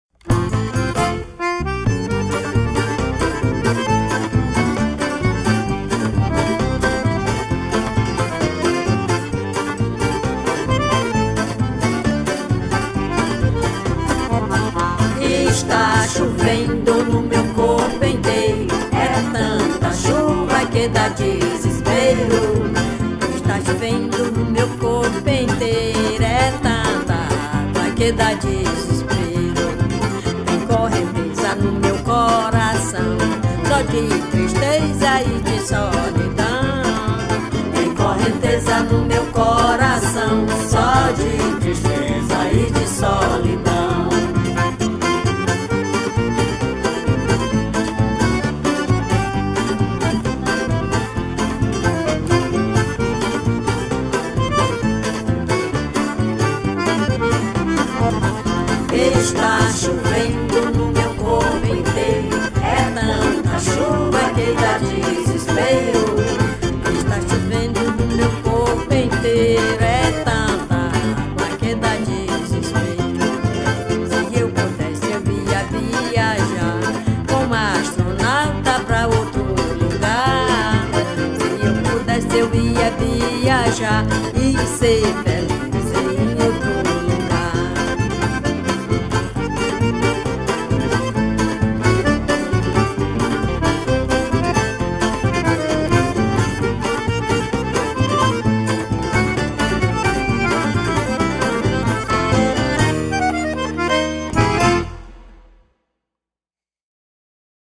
1247   01:48:00   Faixa:     Marcha